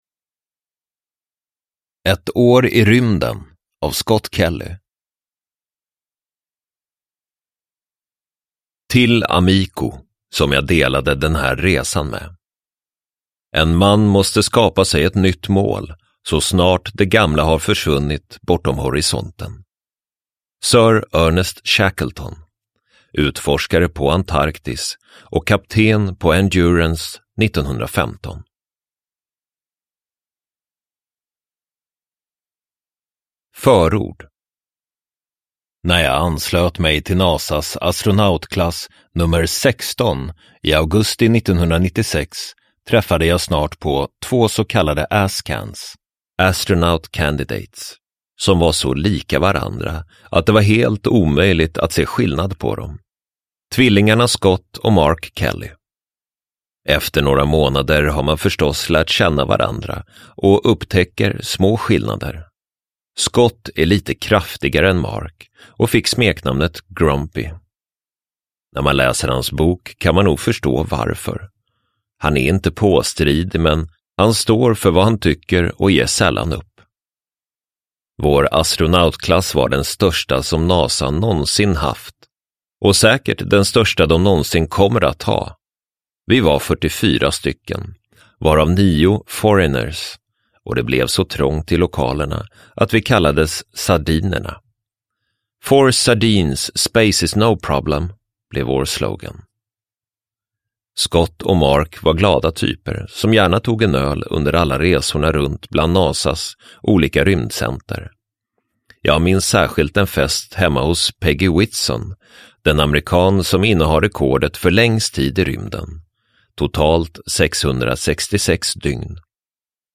Ett år i rymden : en livstid av upptäckter – Ljudbok – Laddas ner